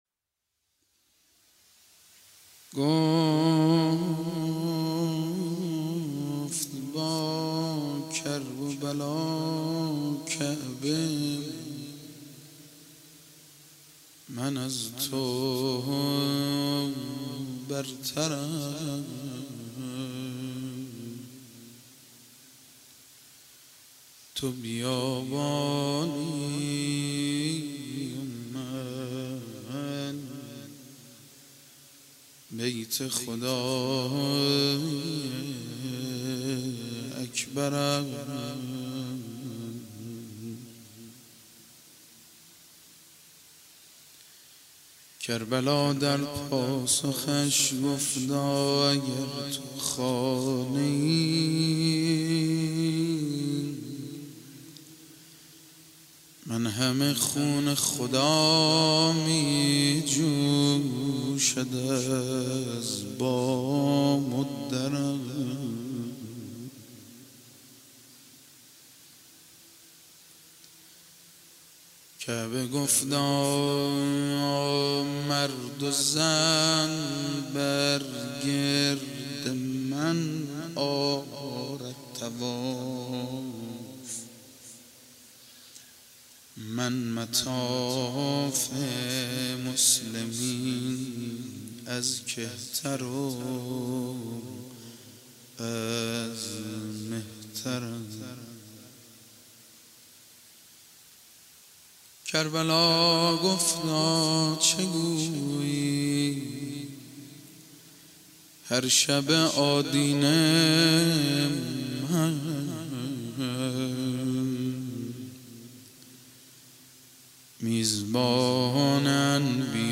شب دوم محرم
01-Rozeh.mp3